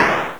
assault_rifle.wav